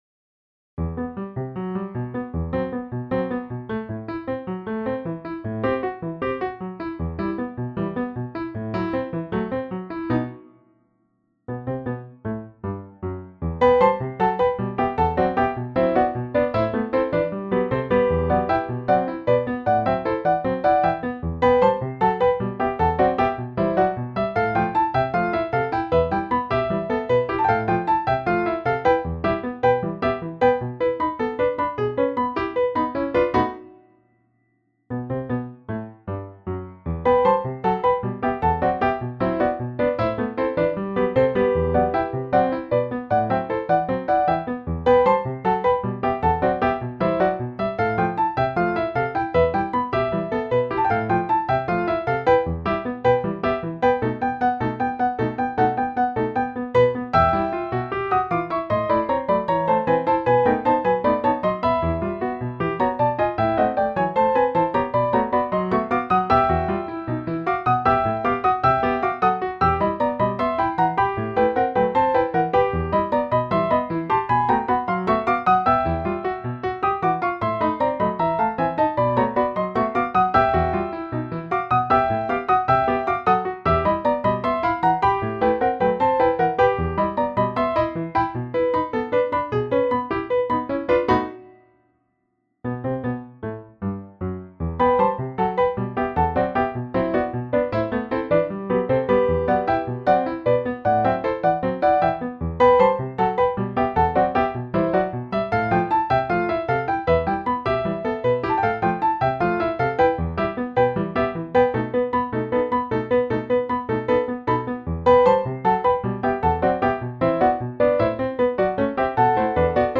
Piano duet
Piano duet 1st part easy